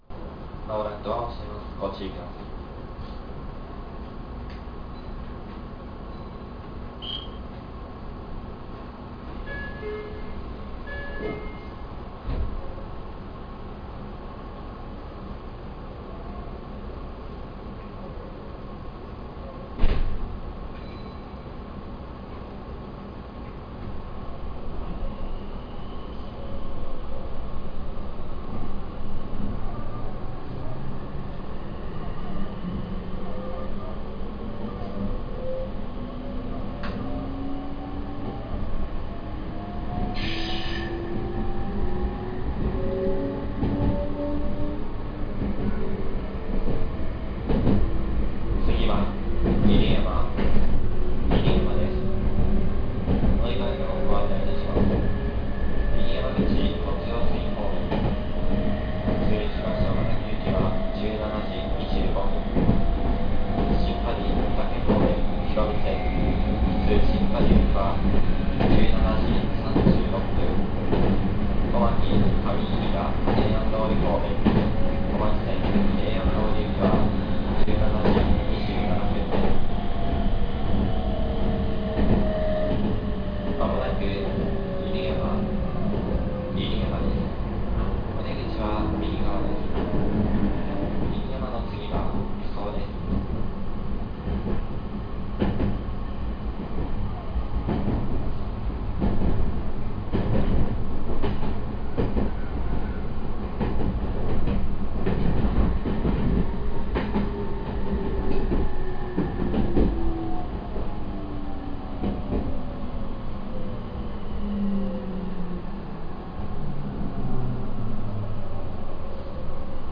〜車両の音〜
・3100系（三菱IGBT）走行音
【犬山線】犬山遊園→犬山（2分7秒：996KB）
3100M_InuyamaYuen-Inuyama.mp3